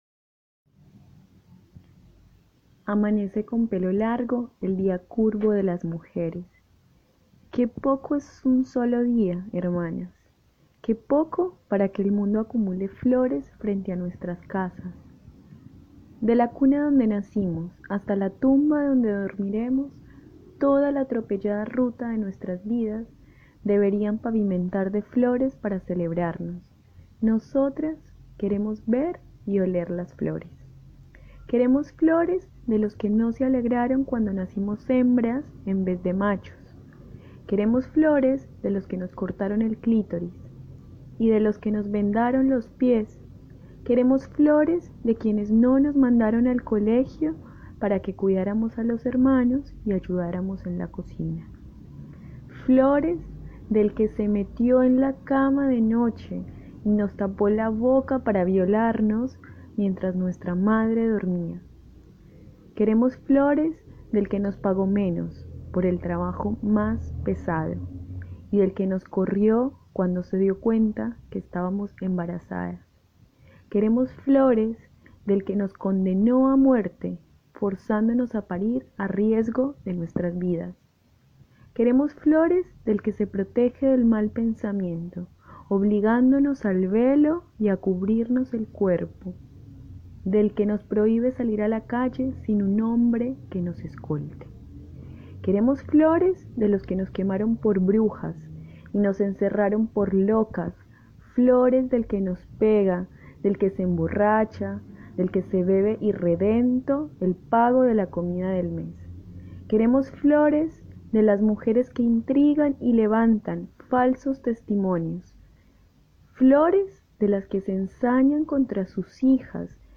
«8 de marzo» poema
poemagiocondabelli.mp3